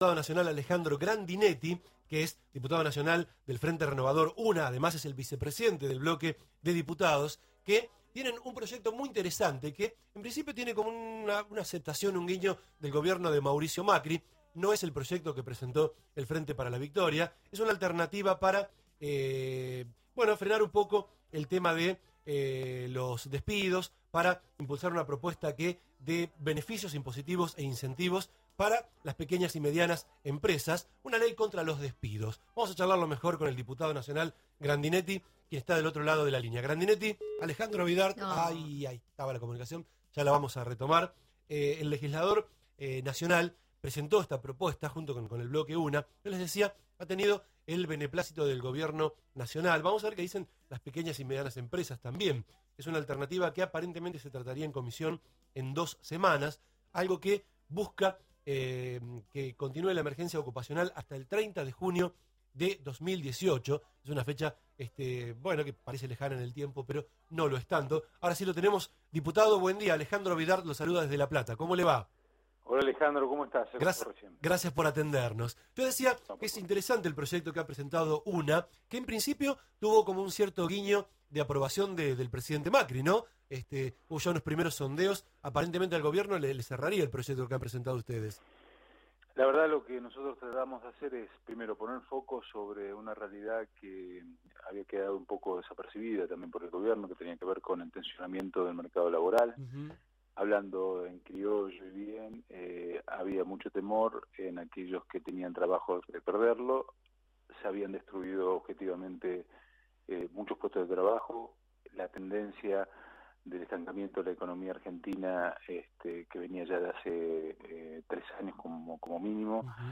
En diálogo con LA REDONDA 100.3, el legislador precisó que con el proyecto, que entre otras cosas busca que continúe la emergencia ocupacional hasta el 30 de junio de 2018, se intenta poner foco en los problemas de empleo que hay en la Argentina inyectando recursos fiscales que estimulen la actividad.